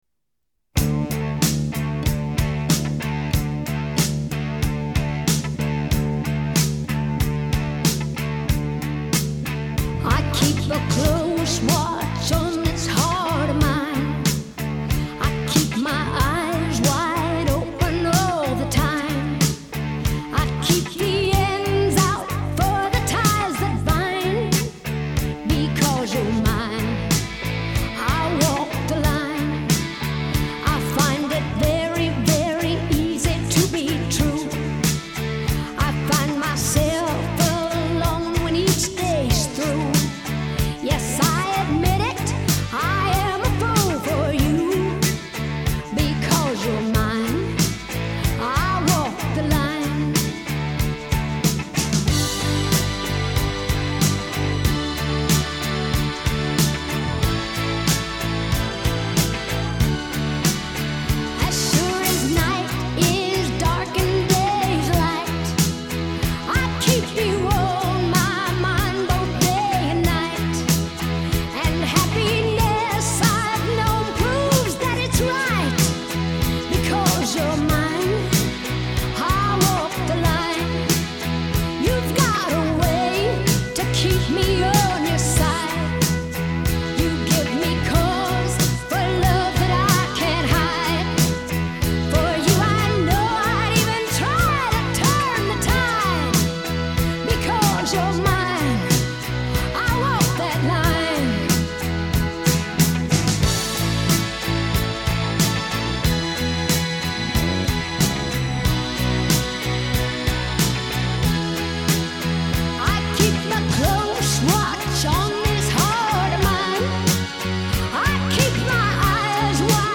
Жанр: Ballad, Folk, World, & Country, Pop Rock, Country Rock